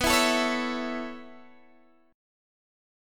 B9sus4 chord